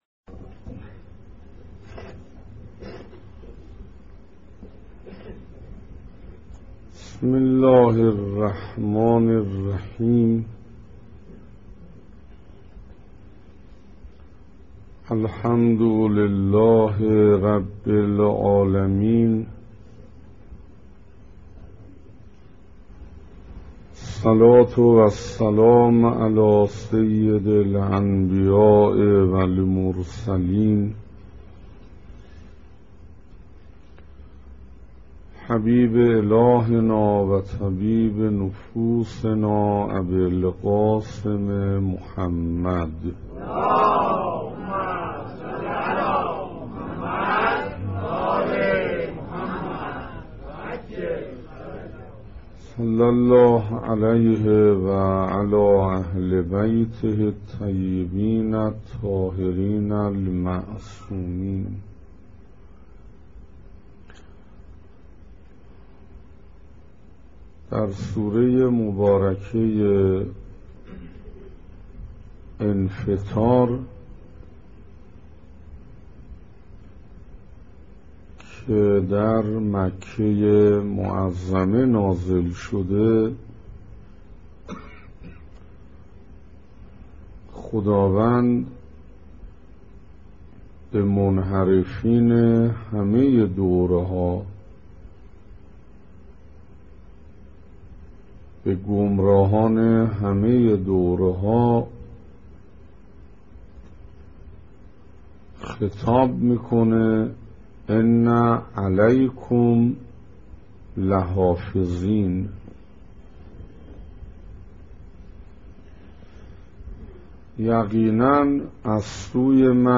سخنراني هفتم